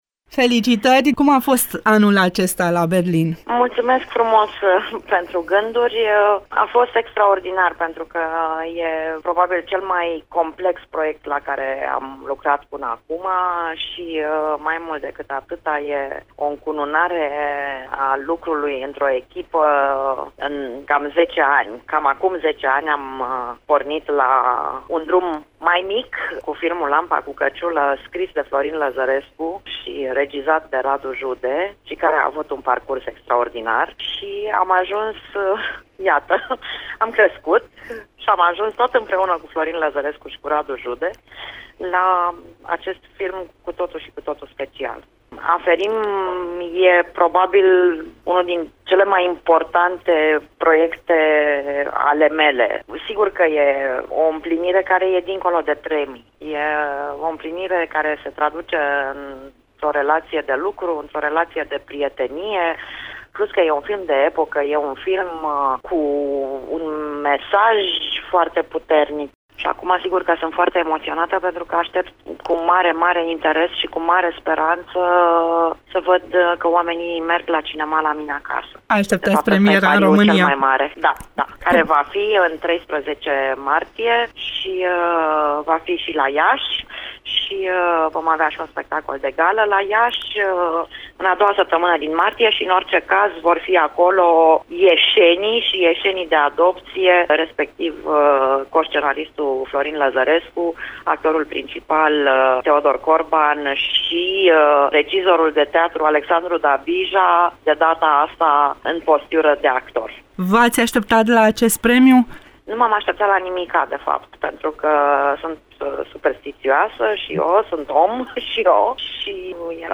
Într-un interviu acrodat în EXCLUSIVITATE pentru Radio România Iaşi, Ada Solomon dezvăluie că o parte din echipa Aferim! va fi prezentă la Iaşi în a doua săptămâna din luna martie, atunci când va fi organizat un spectacol de gală.